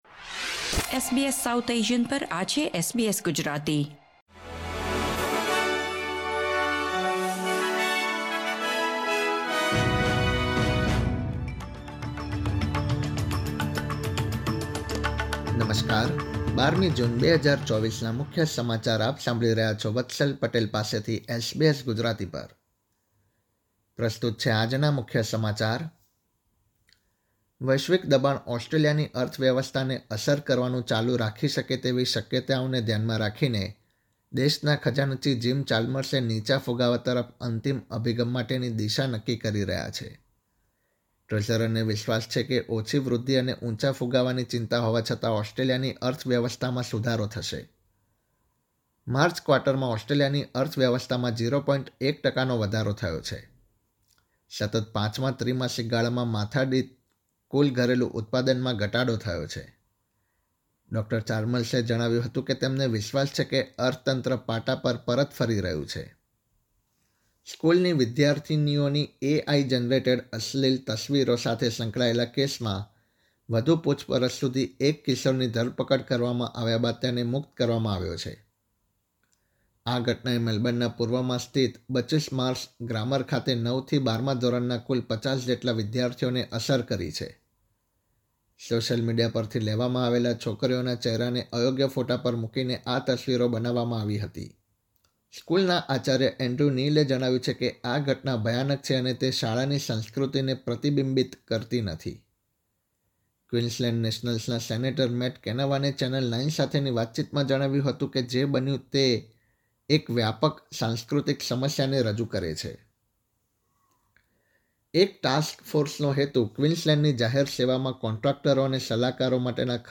SBS Gujarati News Bulletin 12 June 2024